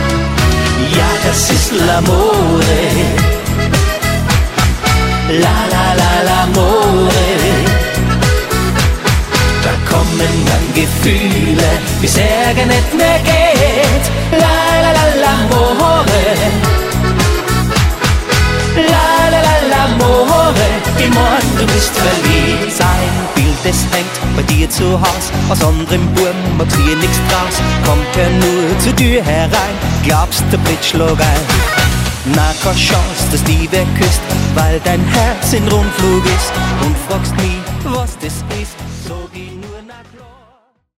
Die Schlagerband mit Herz für jeden Anlass